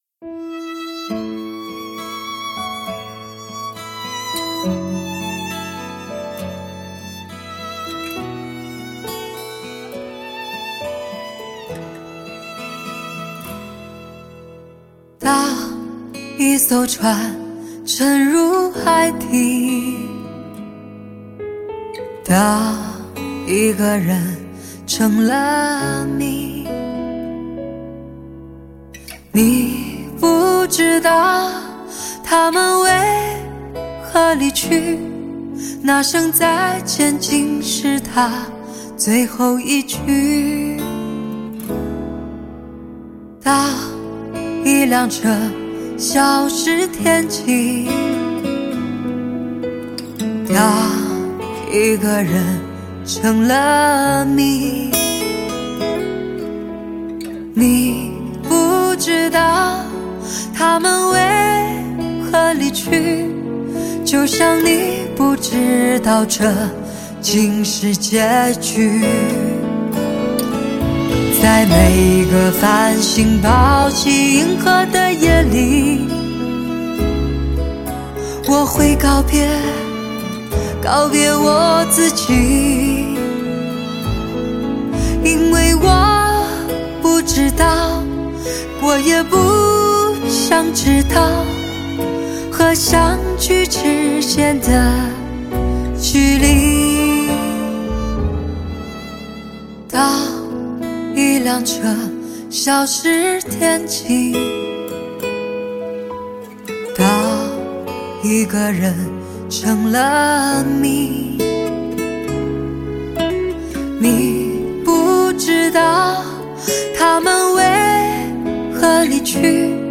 寂寞似梦，风情万种，让人心痛。
高清音质，打造完美品质。
高级音响专业发烧示范大碟